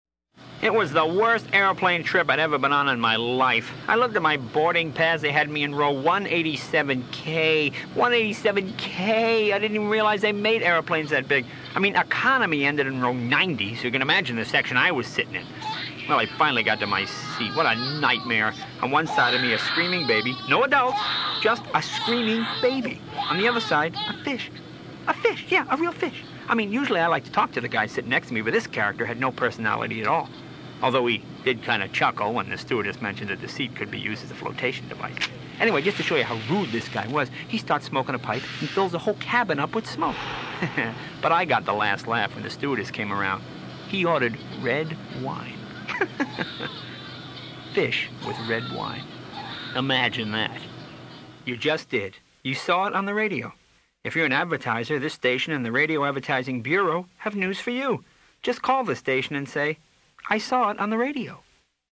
Meanwhile, here are a few classic pro-Radio promos for your listening enjoyment (they are not authorized for airplay), courtesy of the Radio Advertising Bureau.